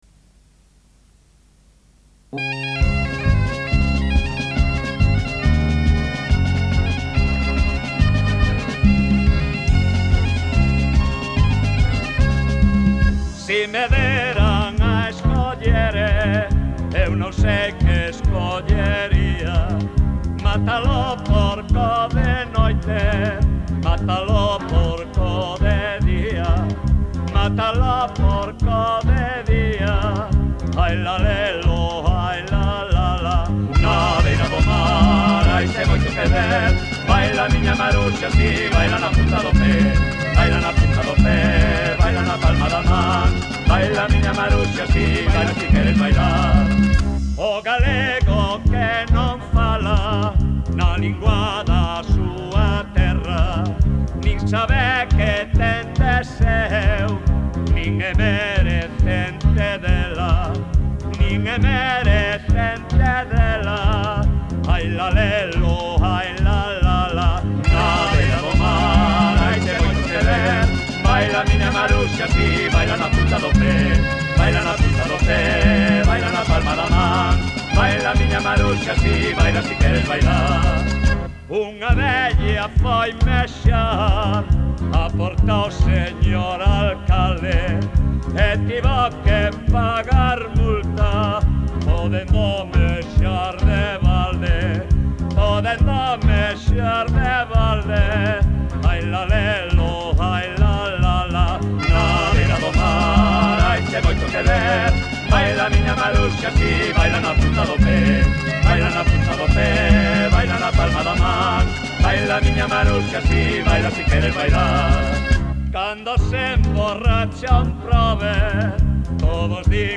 Música:Popular